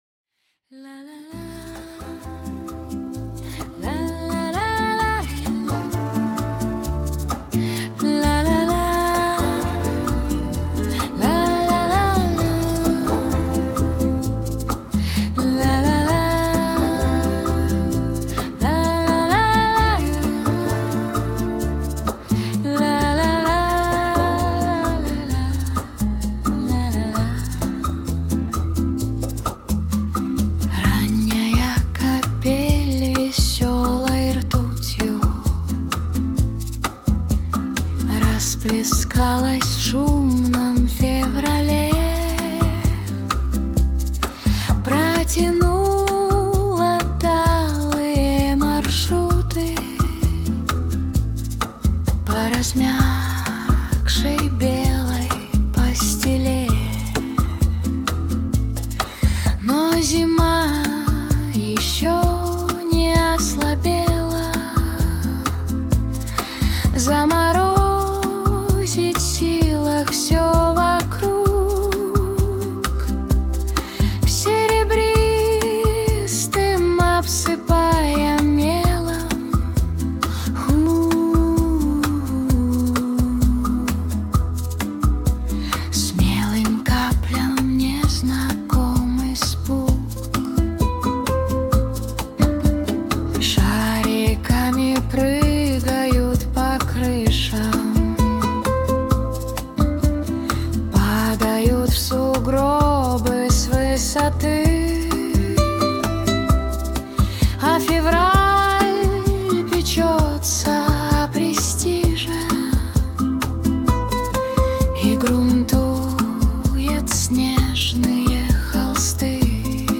• Жанр: Авторская песня